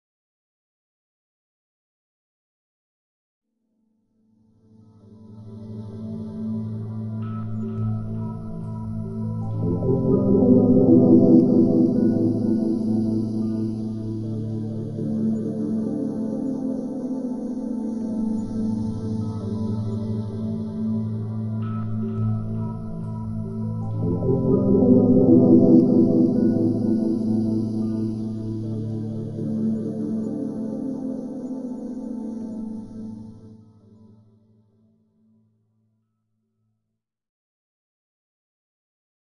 描述：缓慢的消声起泡洗，来自于一个已经被删除的自制浪花的组合。
标签： 环境 醇厚 光滑 柔软
声道立体声